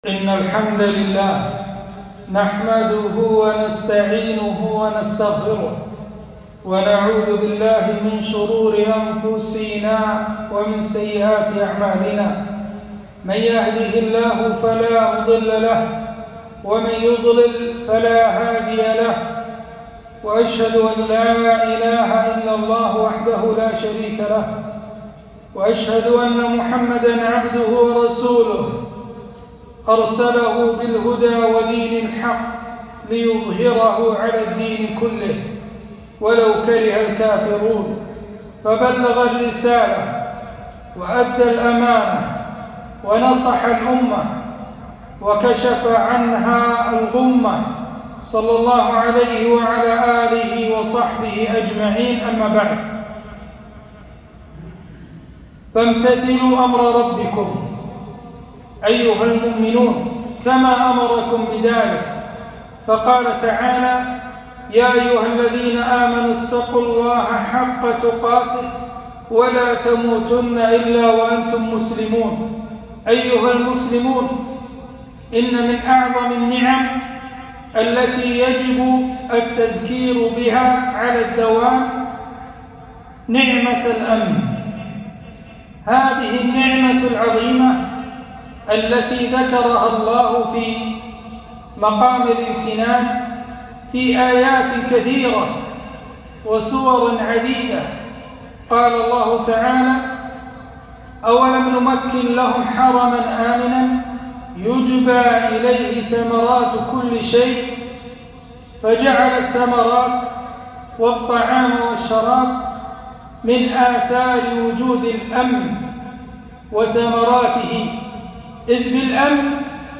خطبة - تذكير العباد بنعم الله على البلاد